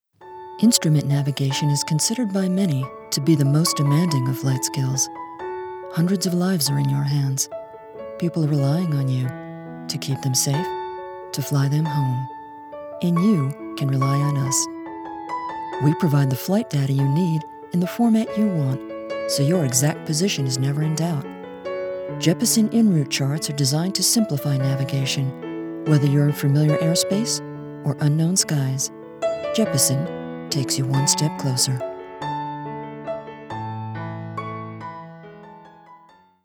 Female
My voice is neutral American English - best described as low, rich, smooth, elegant, sultry, intelligent, smokey, confident and sophisticated - well suited for luxury & automotive, commercial, high tech and documentary.
Corporate
Trustworthy Corporate Promo